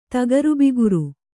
♪ tagarubiguru